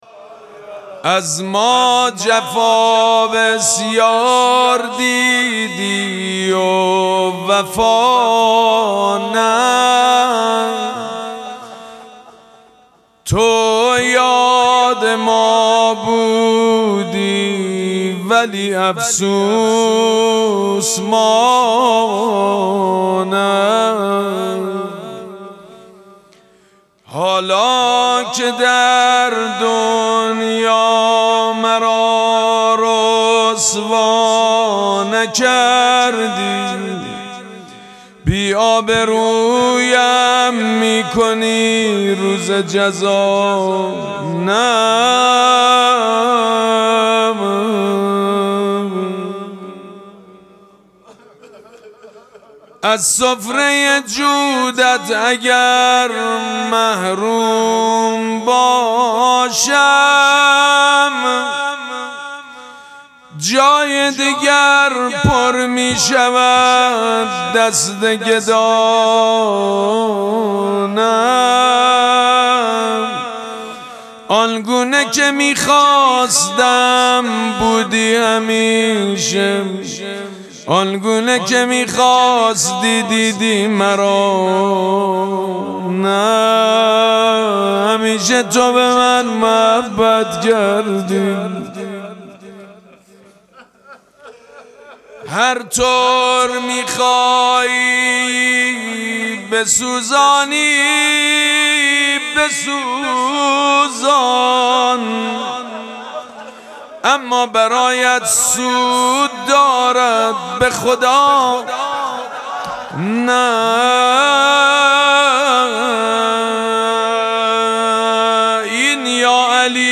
مراسم مناجات شب پنجم ماه مبارک رمضان
مناجات
مداح
حاج سید مجید بنی فاطمه